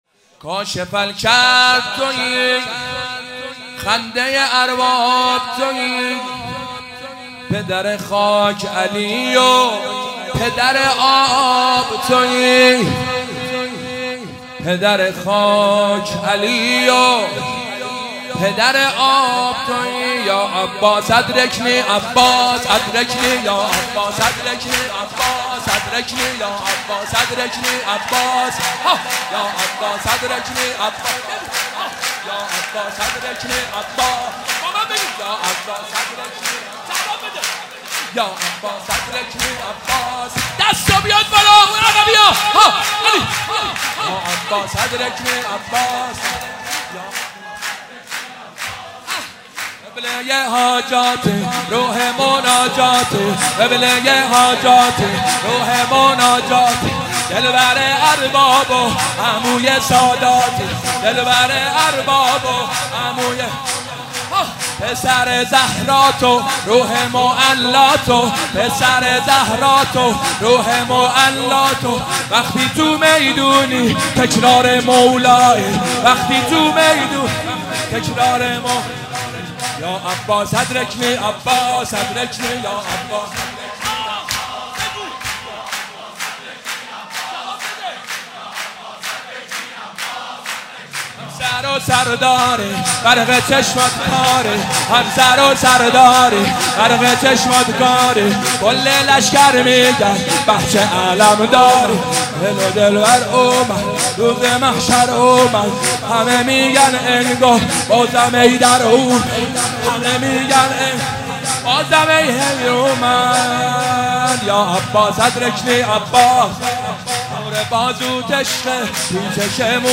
مداحی
ولادت حضرت عباس (ع)